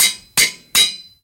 anvil_use.mp3